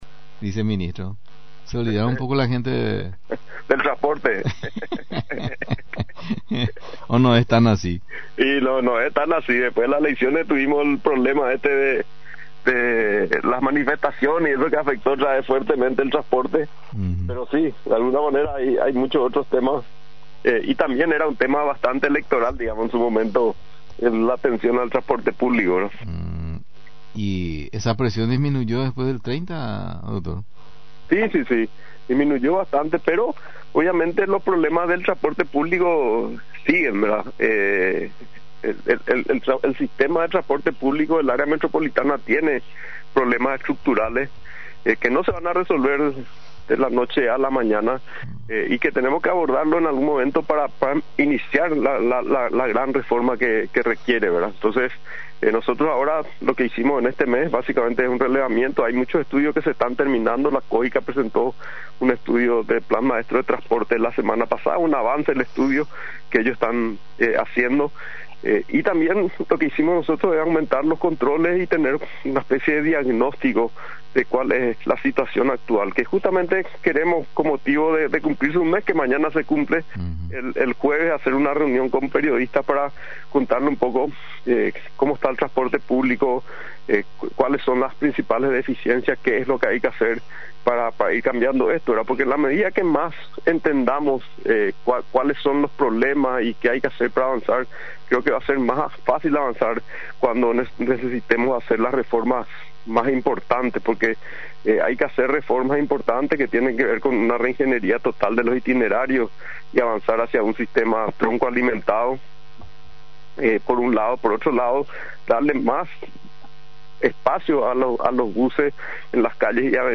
En comunicación con la 920 AM, el Viceministro de Transporte Oscar Stark, habló de las diferentes problemáticas del sector al cumplir 30 dias de asumir, de un registro de 2.500 buses autorizados como meta que significa un 75 por ciento que son 1.900, pero en realidad se cuenta un poco más de 1.600 operando, con una falta de más de 200 para llegar al porcentaje requerido, indicó entre otras cosas.